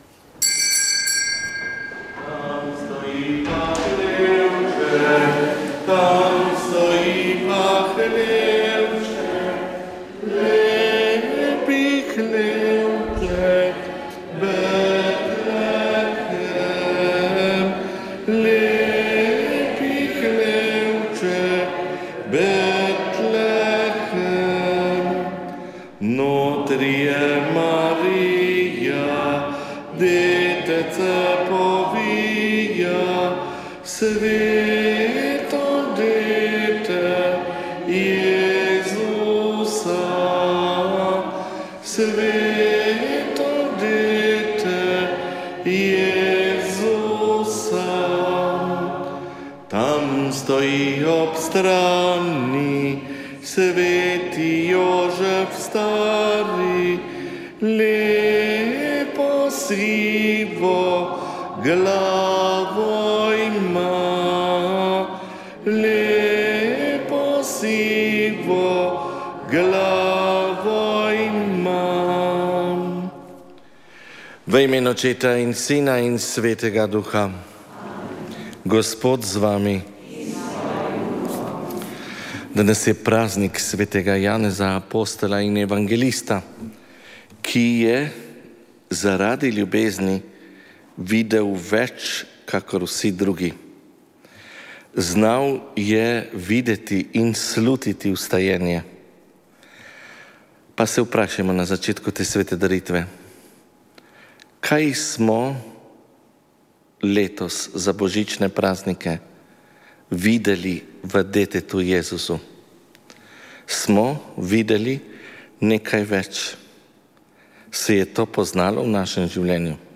Sv. maša iz cerkve sv. Marka na Markovcu v Kopru 26. 12.